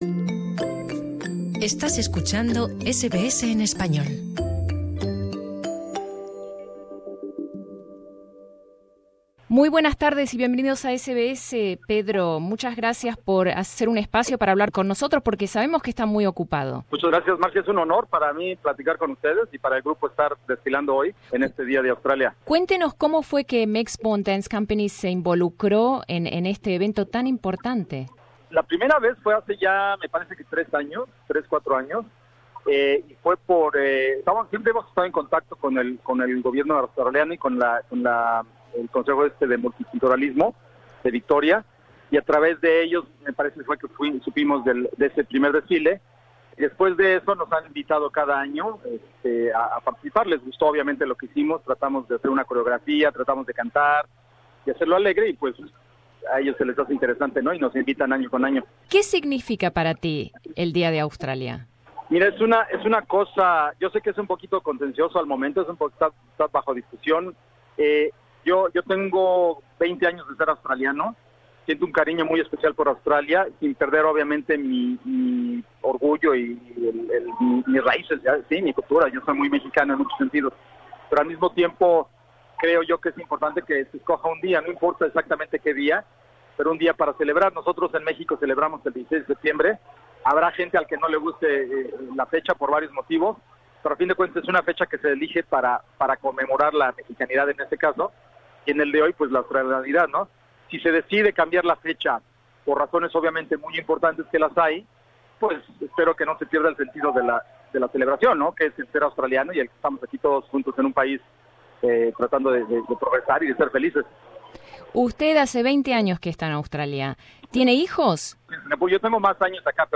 Desfile del Día de Australia Source: Mexbourne Dance Co.